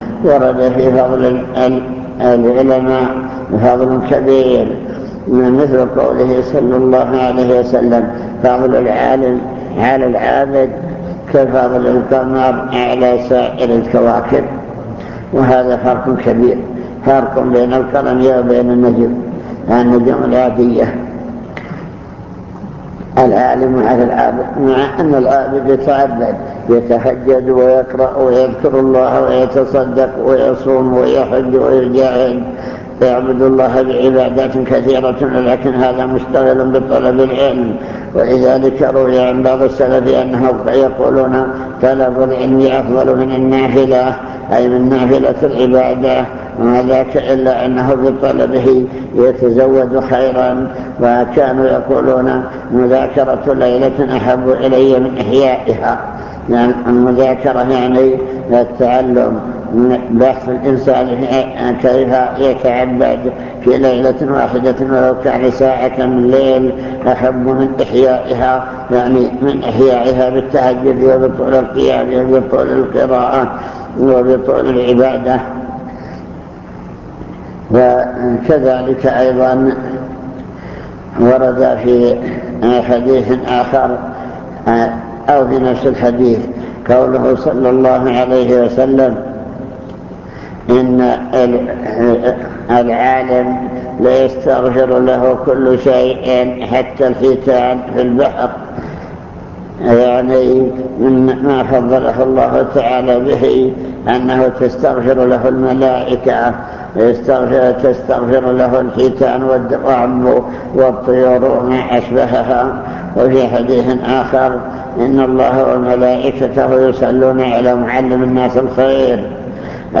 المكتبة الصوتية  تسجيلات - لقاءات  كلمة حول طلب العلم